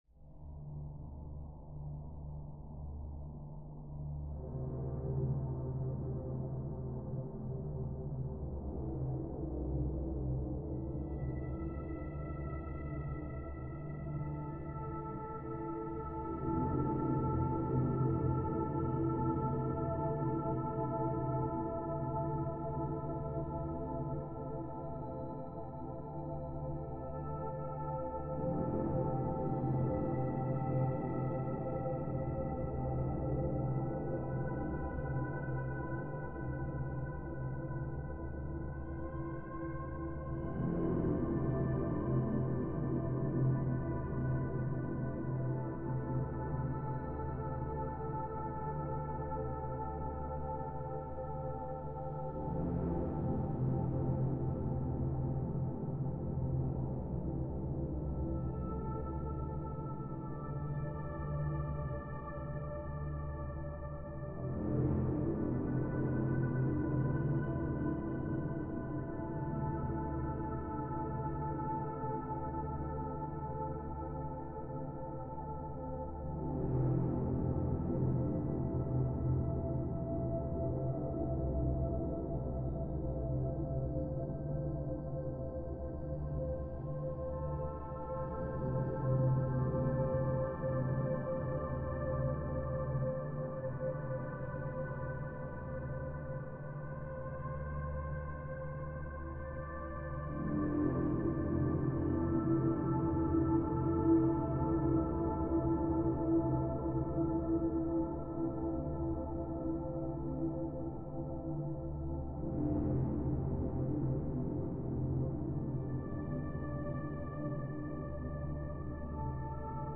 background-music-dark-spectrometer.mp3